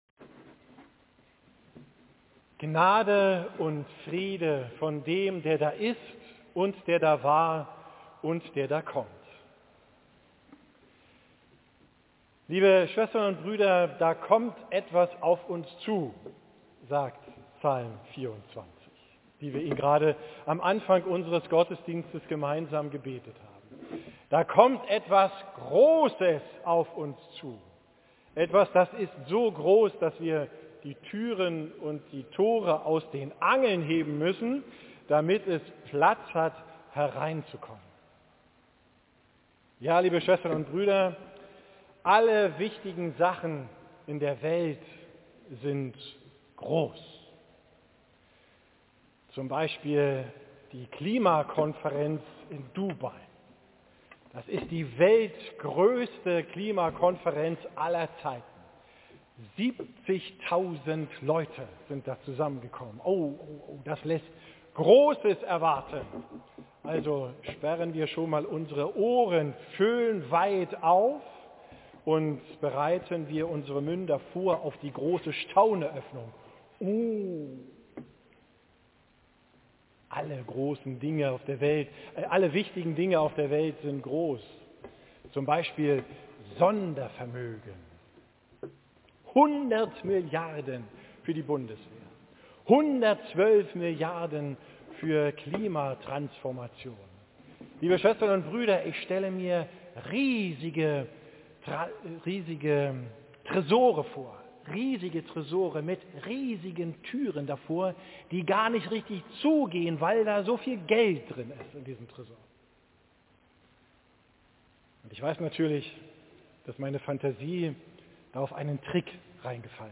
Predigt vom 1. Sonntag im Advent, 3.